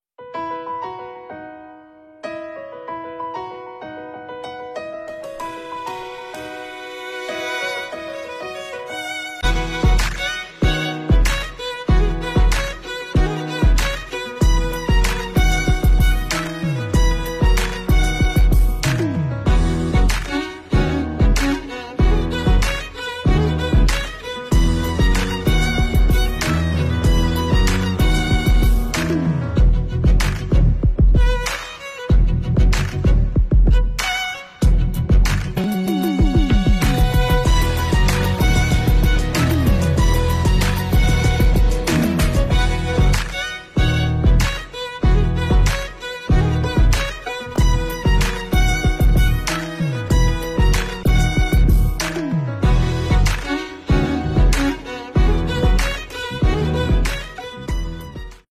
市税务局作为6个发言单位之一在大会上作了成果汇报。
市税务局党委书记、局长吕环海在“解放思想、转变作风、提升能力”专项行动成果展示汇报会上作成果汇报发言。
汇报会和专题党课以电视电话会议形式召开，东兴市设分会场。